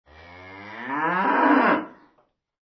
Descarga de Sonidos mp3 Gratis: muu animal 1.